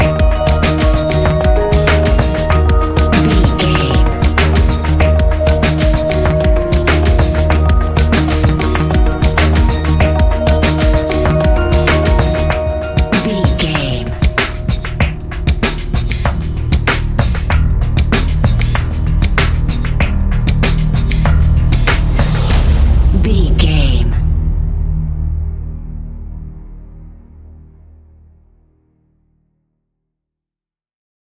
Ionian/Major
D♯
electronic
techno
trance
synths
synthwave
glitch